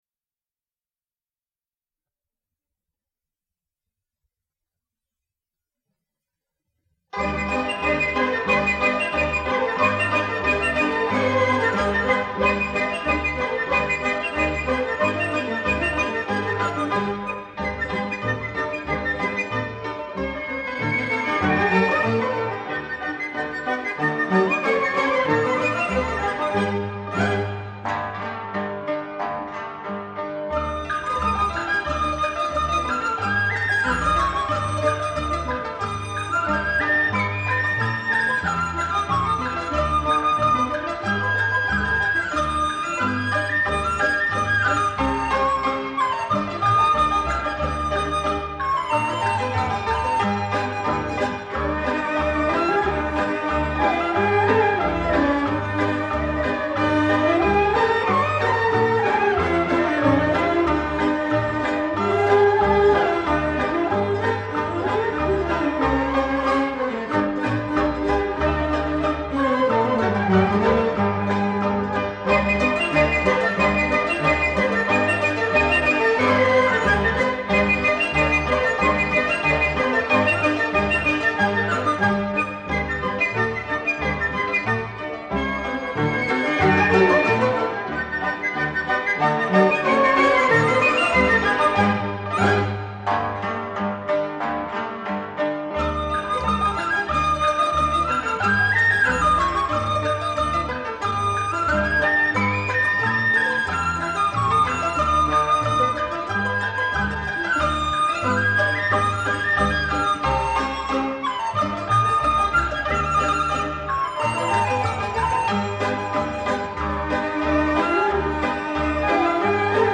音乐类型: 民乐
资源出处(Credit)：原版卡式磁带电脑录制
小合奏